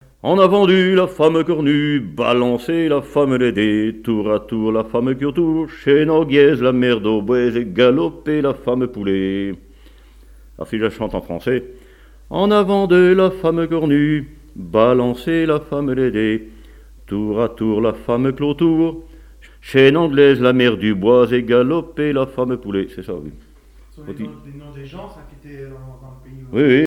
Chants brefs - A danser Résumé : En avant deux la femme à Cornu, Balancez la femme à Laidet, Tour à tour la femme Clautour, chaine anglaise la mère Dubois, Galopez la femme Soulet.
branle : avant-deux
Pièce musicale inédite